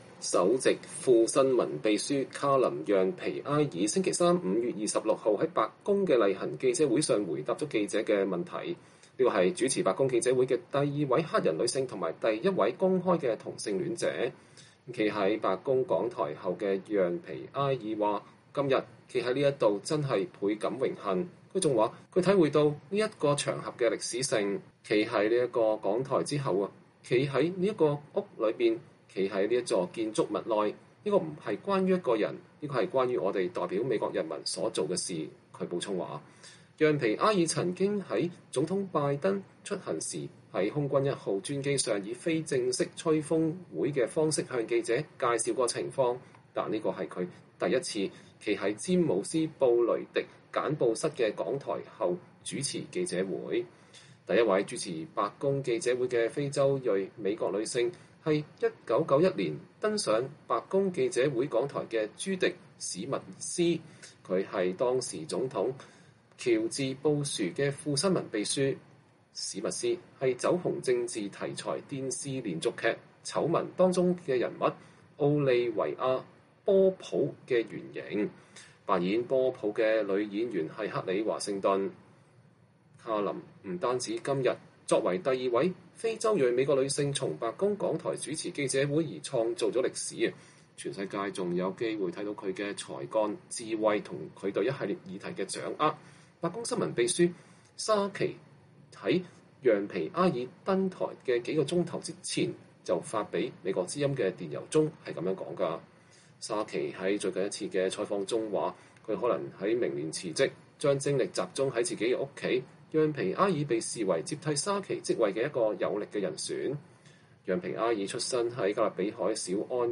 白宮首席副新聞秘書讓-皮埃爾主持白宮記者會，寫下歷史。(2021年5月26日)